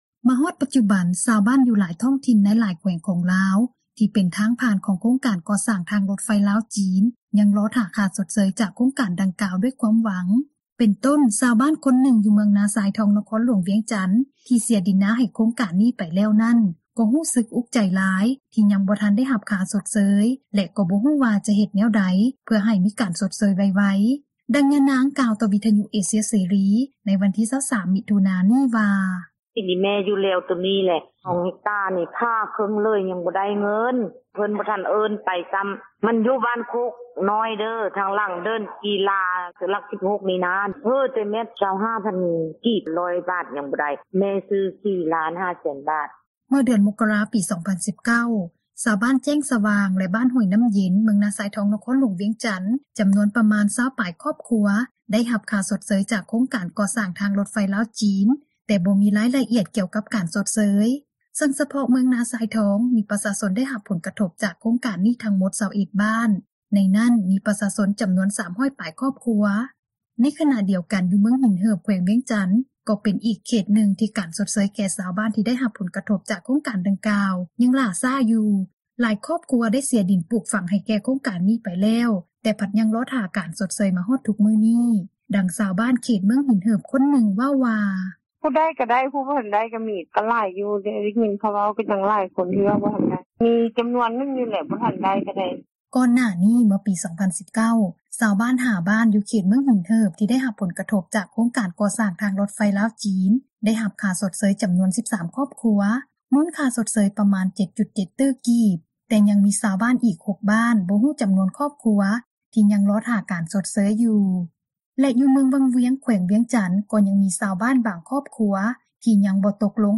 ໃນຂນະດຽວກັນ ຢູ່ເມືອງຫິນເຫີບ ແຂວງວຽງຈັນ ກໍເປັນອີກເຂດນຶ່ງ ທີ່ການຊົດເຊີຍແກ່ຊາວບ້ານ ທີ່ໄດ້ຮັບຜົລກະທົບຈາກໂຄງການ ດັ່ງກ່າວ ຍັງຫຼ້າຊ້າຢູ່, ຫຼາຍຄອບຄົວ ໄດ້ເສັຍດິນປູກຝັງໃຫ້ແກ່ ໂຄງການໄປແລ້ວ ແຕ່ພັດຍັງລໍຖ້າ ການຊົດເຊີຍມາຮອດທຸກມື້ນີ້, ດັ່ງຊາວບ້ານ ເຂດເມືອງຫິນເຫີບ ຄົນນຶ່ງເວົ້າວ່າ:
ສ່ວນຢູ່ນະຄອນຫຼວງພຣະບາງ ແຂວງຫຼວງພຣະບາງ ກໍຍັງມີອີກບາງຄອບຄົວ ທີ່ຍັງບໍ່ທັນໄດ້ຮັບຄ່າຊົດເຊີຍເທື່ອ ເຖິງແມ່ນວ່າການກໍ່ສ້າງ ທາງຣົດໄຟ ໄດ້ຄືບໜ້າໄປຫຼາຍແລ້ວກໍຕາມ, ດັ່ງຊາວບ້ານ ໃນນະຄອນຫຼວງພຣະບາງ ຄົນນຶ່ງ ເວົ້າວ່າ: